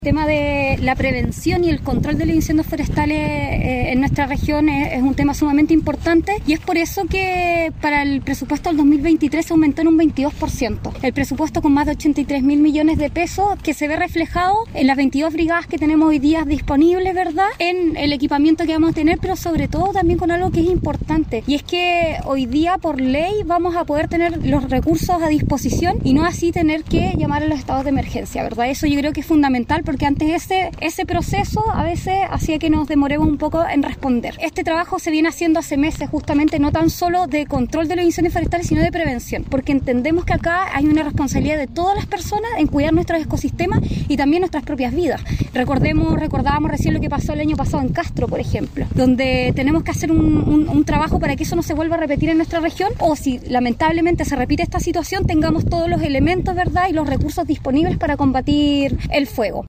La actividad, realizada en el Aeródromo La Paloma, en Puerto Montt, contó con la presencia de autoridades regionales, Fuerzas Armadas, Bomberos, municipios e invitados especiales.
Al respecto, la delegada presidencial de la Región de Los Lagos, Giovana Moreira, resalto en la oportunidad que “el tema de la prevención en la región es muy relevante”: